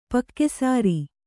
♪ pakke sāri